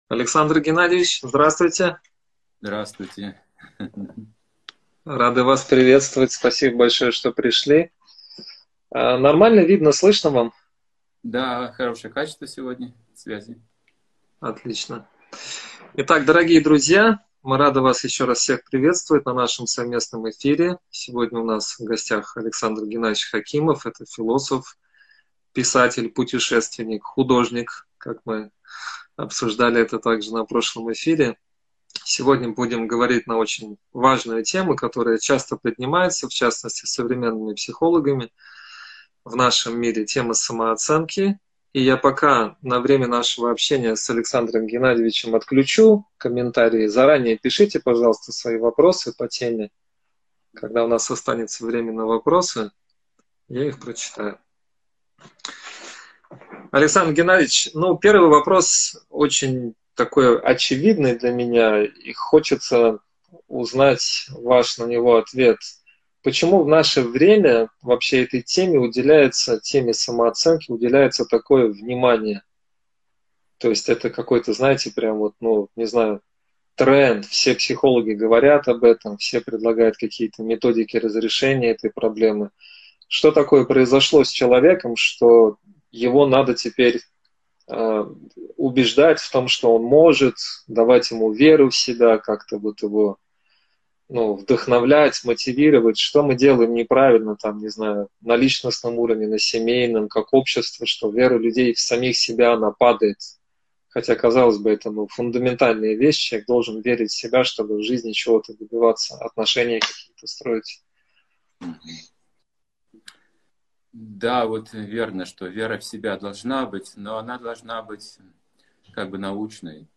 Алматы, Беседа